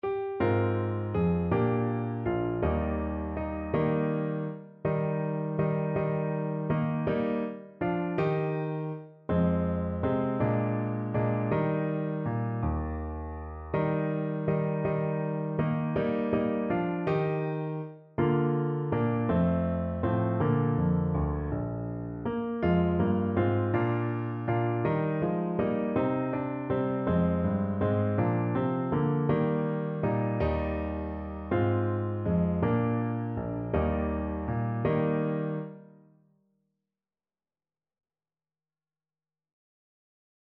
3/4 (View more 3/4 Music)
One in a bar .=c.54
Traditional (View more Traditional Saxophone Music)